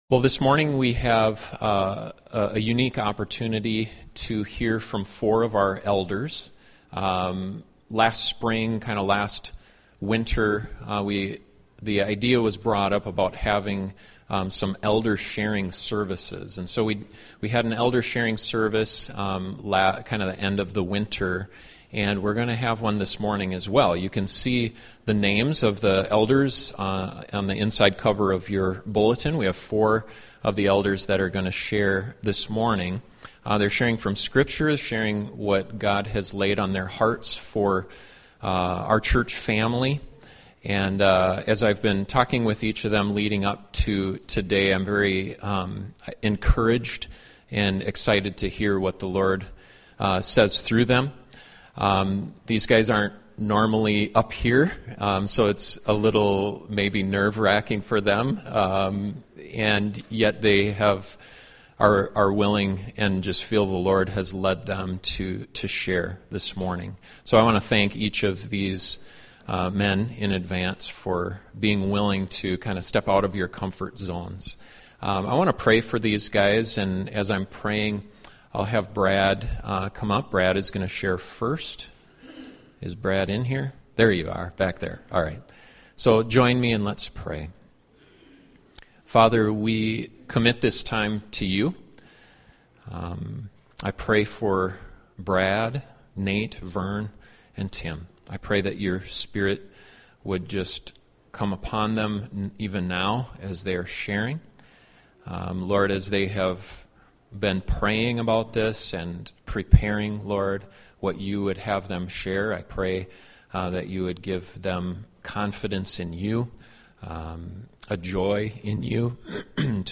Four of the elders at East Lincoln share what God laid on their hearts for the church.